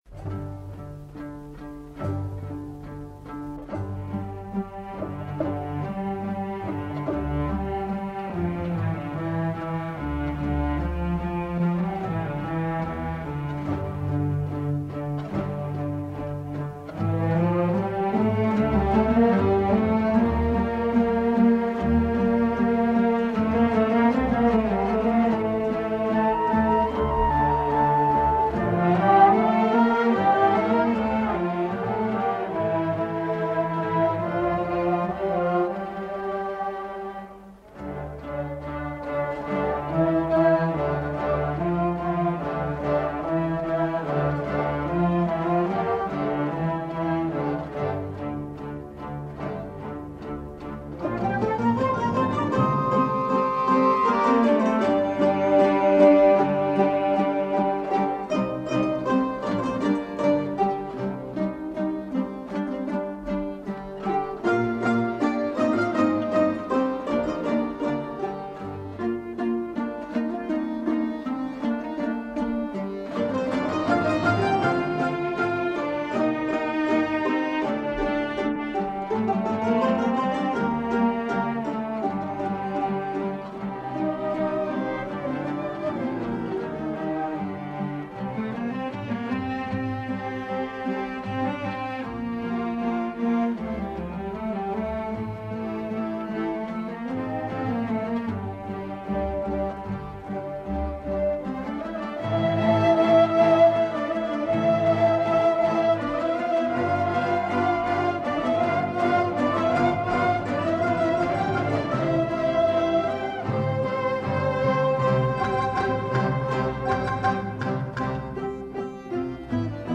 выполненная в жанре казахской классической музыки.